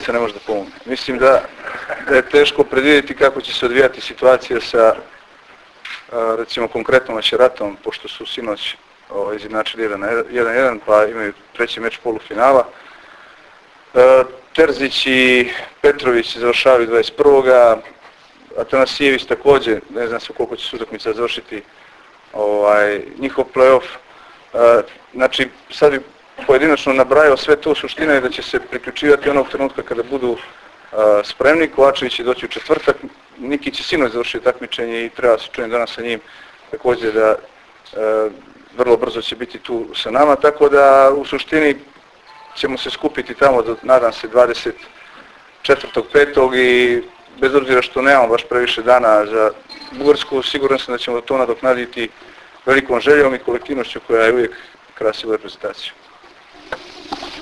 IZJAVA IGORA KOLAKOVIĆA 2